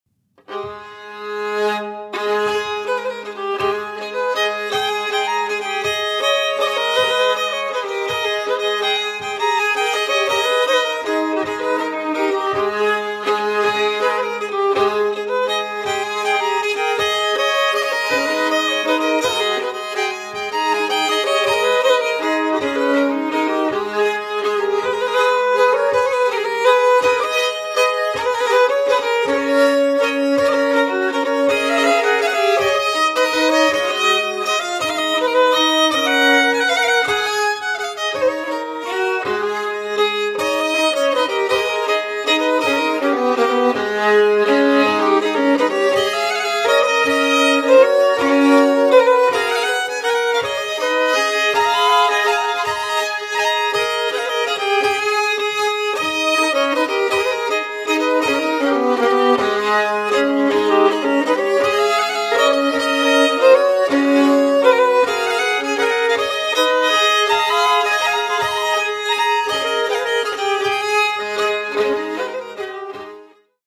Swedish Fiddle w
listen to a sound sample of their fiddling from